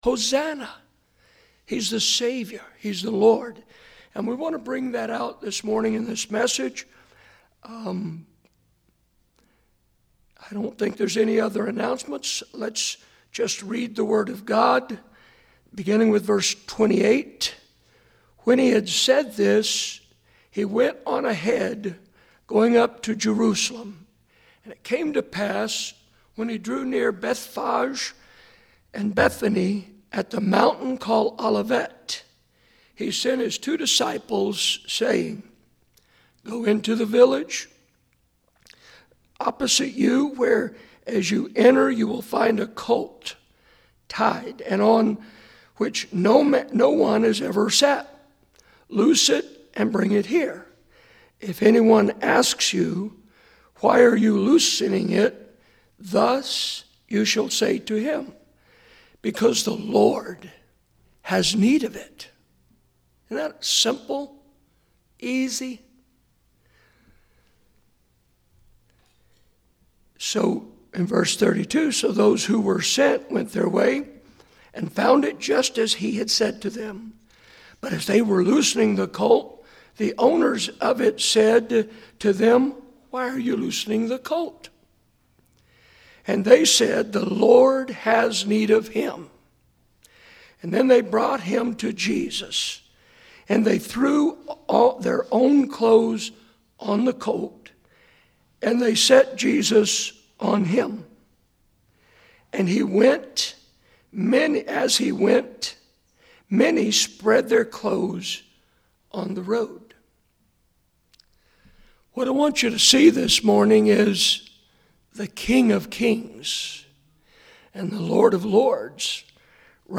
Morning Sermons